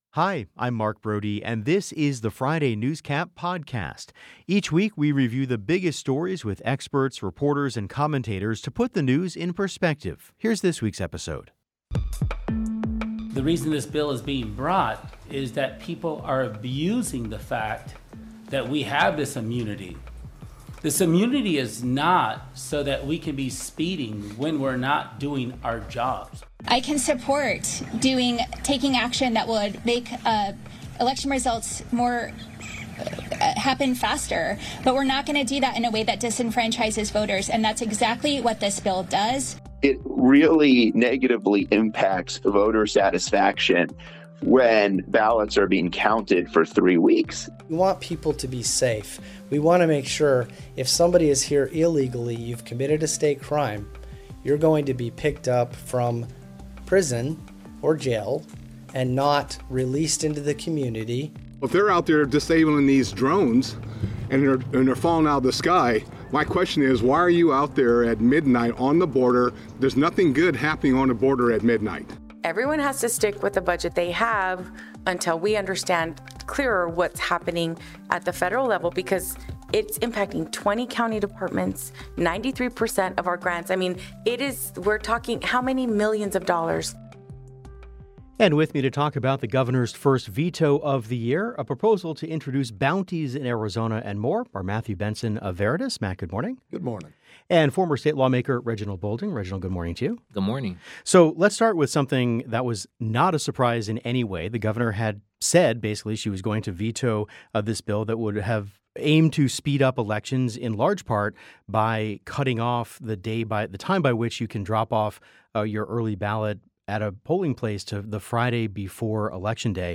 The Show's Friday Newscap is a weekly review of the biggest stories with experts, reporters and commentators to put the news in perspective.